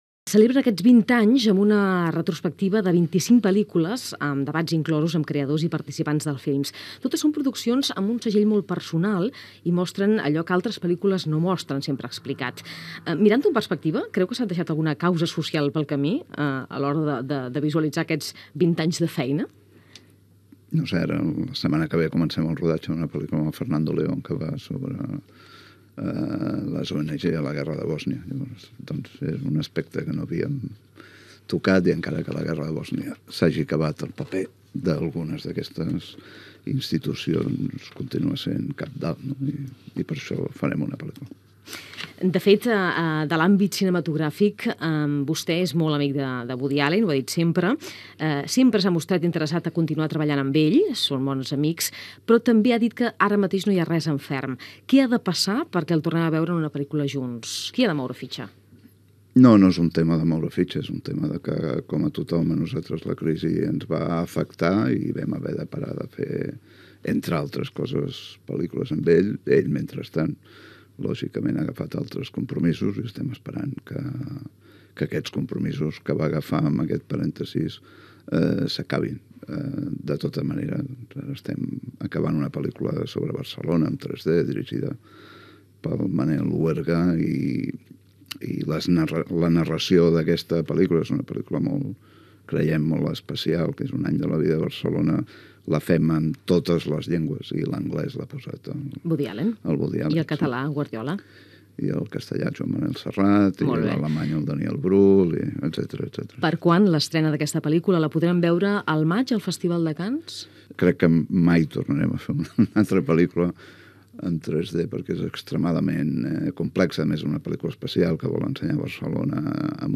Entrevista al cap del grup de comunicació Mediapro, Jaume Roures. S'hi parla del projecte d'una pel·lícula de Fernando León de Aranoa, el documental en 3D sobre Barcelona, la possible compra de Canal Plus per Telefónica
Informatiu